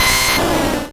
Cri de Florizarre dans Pokémon Rouge et Bleu.